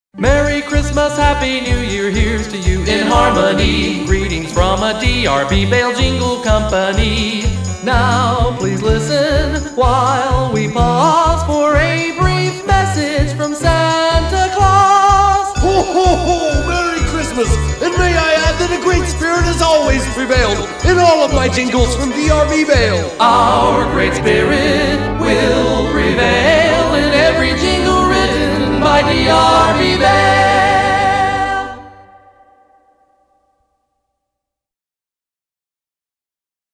Jingles!